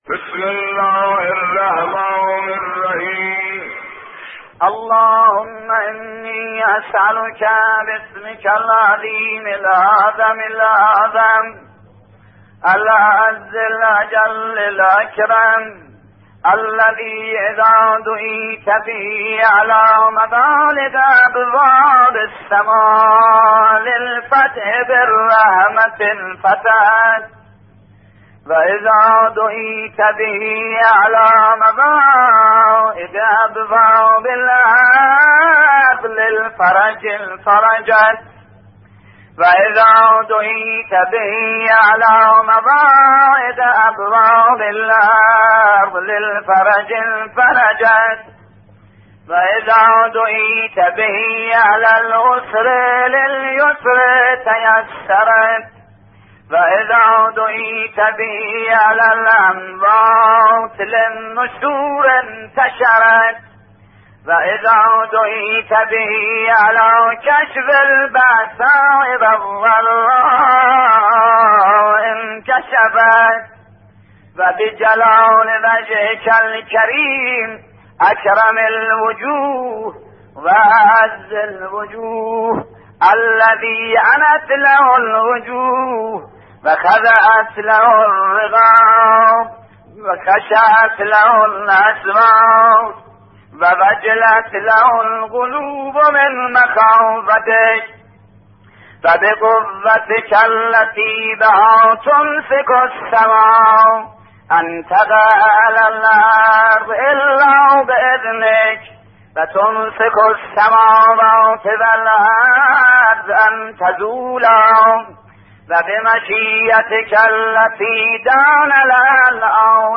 صوت/ "دعای سمات" با نوای شیخ احمد کافی